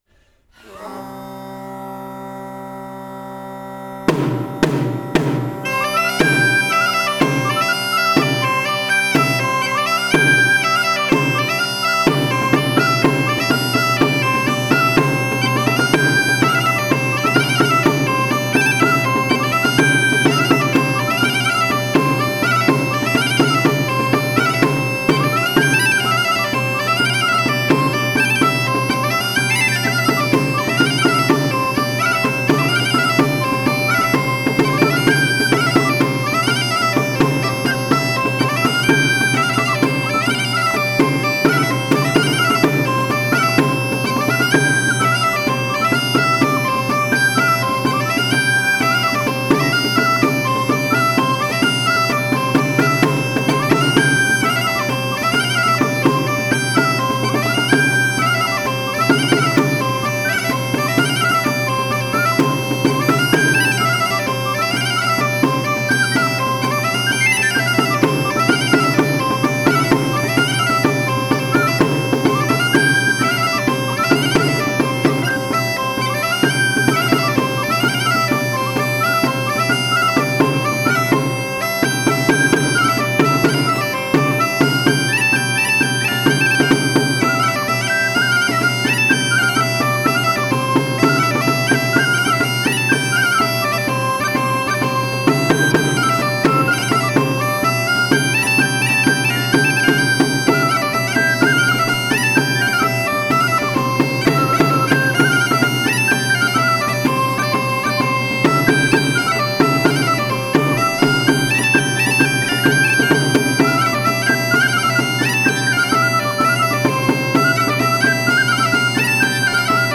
Santa Tecla
15. BALL DE PASTORETS DE TARRAGONA Mija Cobla Mitja Lluna